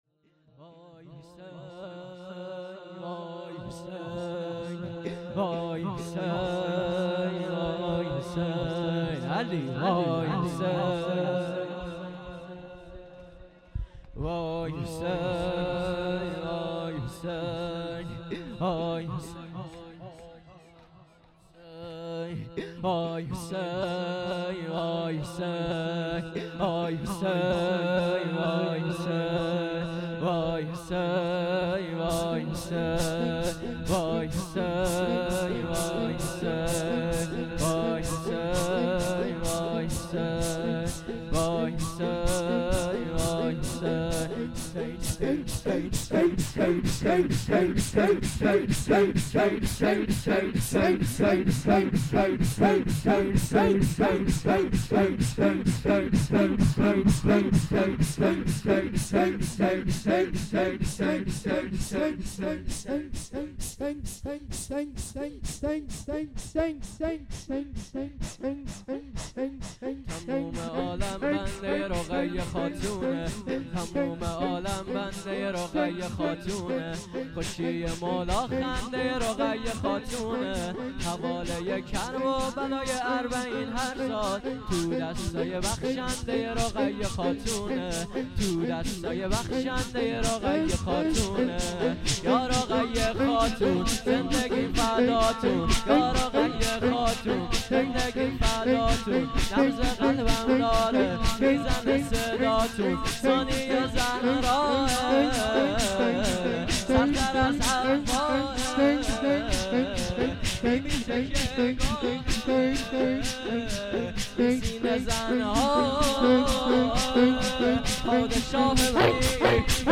شور | تموم عالم بنده ی رقیه خاتونه
دبیرستان امام سجاد علیه السلام | شب سوم
یادمان فاطمیه